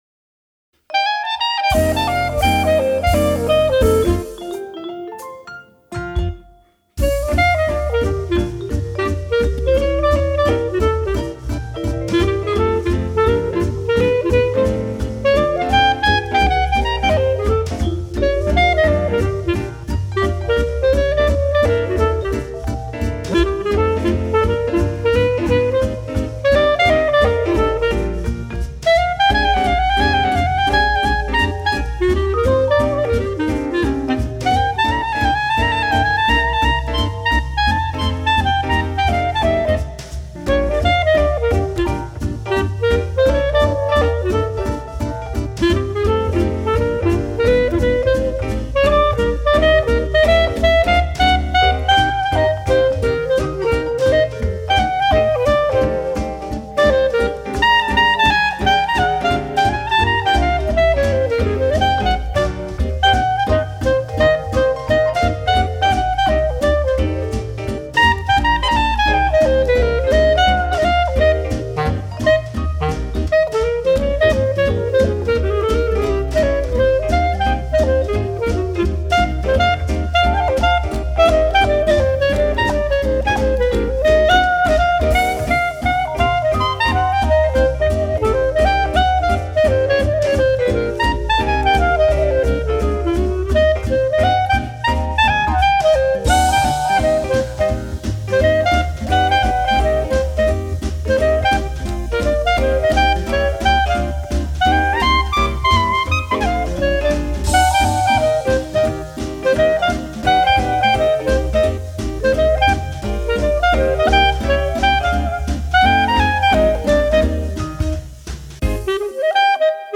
Intermediate/Advanced (AMEB 5th-7th Grade)
arranged for clarinet soloists